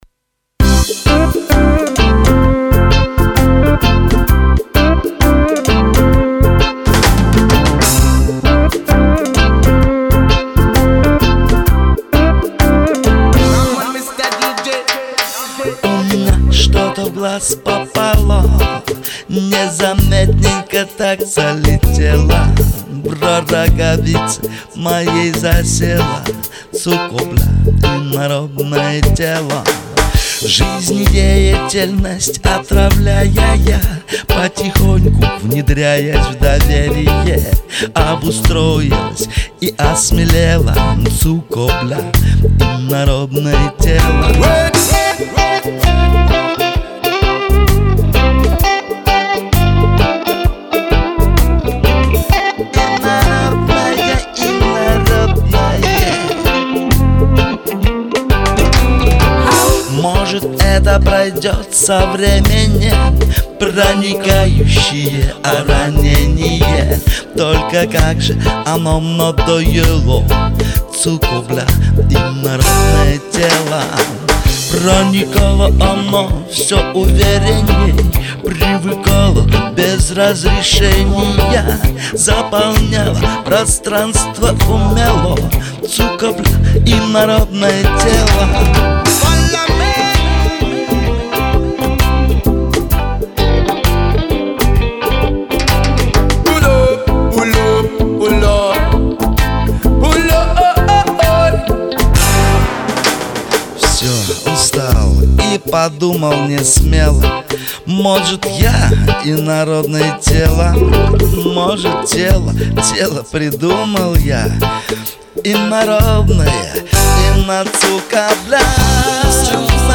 Реггей